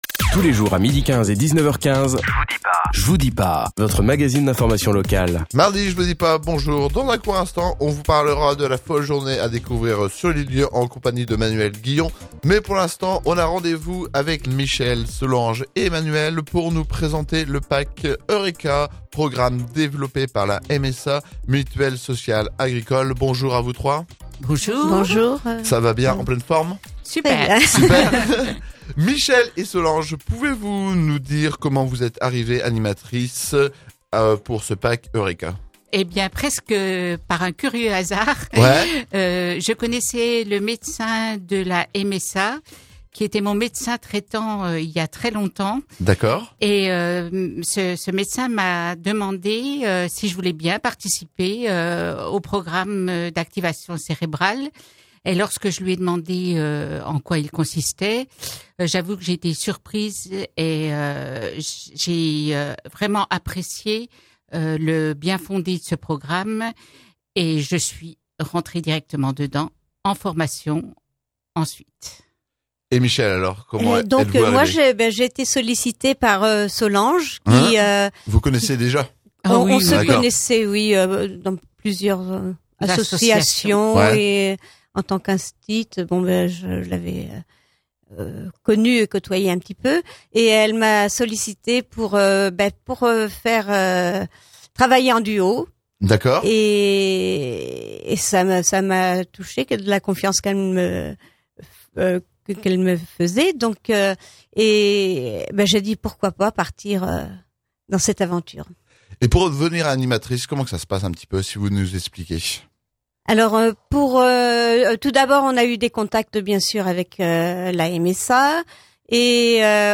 La bibliothèque municipale de La Flèche proposait dimanche 20 octobre 2012 le premier numéro de l’animation « Un dimanche à…